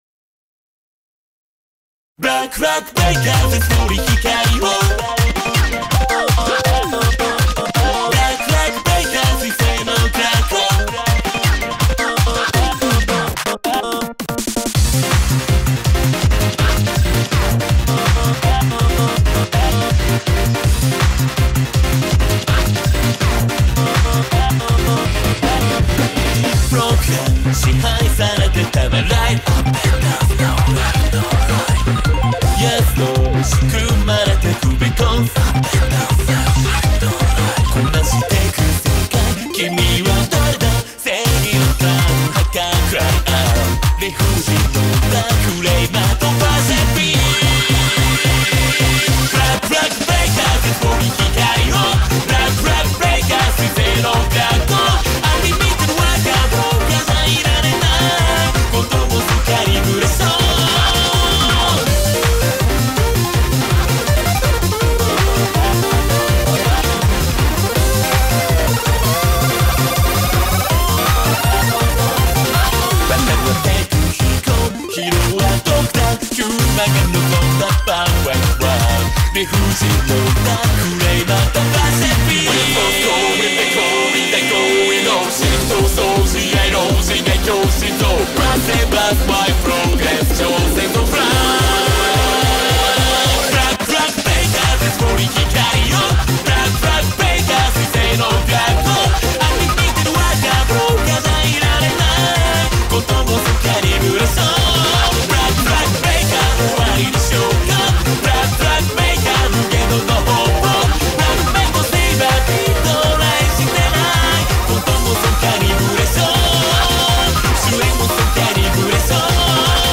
BPM163